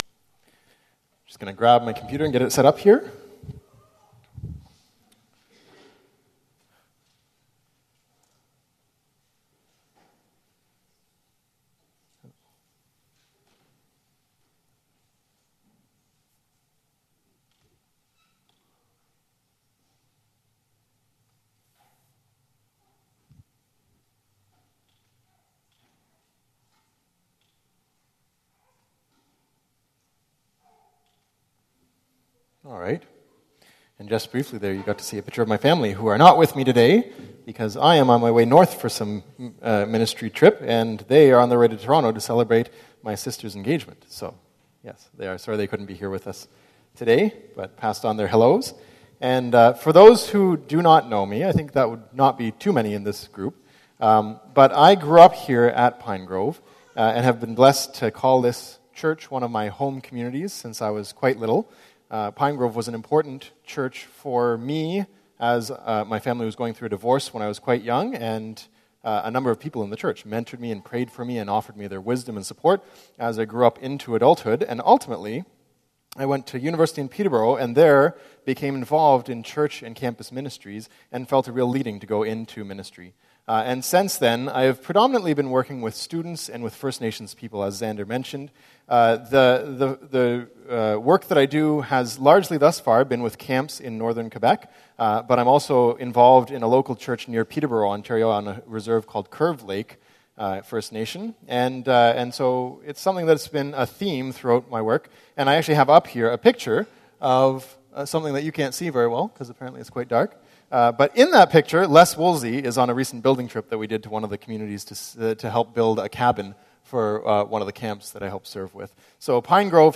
Missional Life Series – Part 6 – This sermon is based Acts 1:6-11.